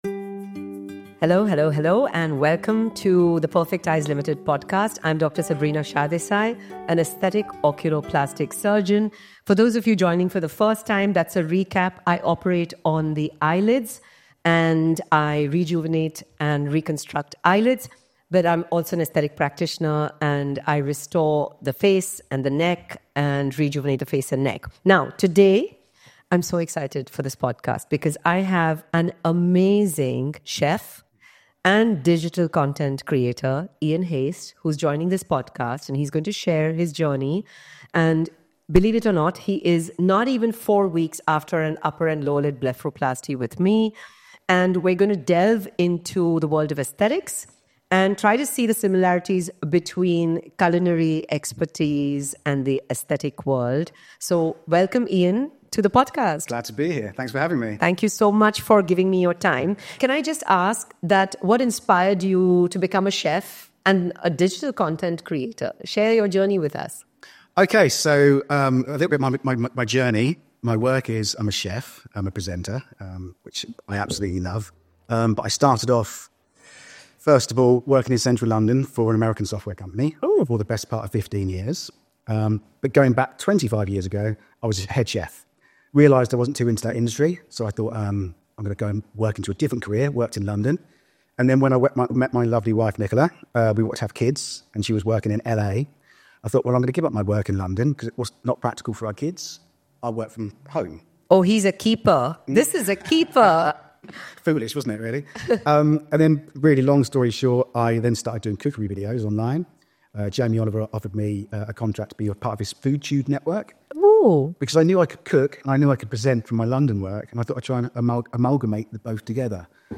Whether you’re considering surgery yourself or simply curious about the experience, this conversation is filled with humour, insight, and practical advice from both patient and practitioner.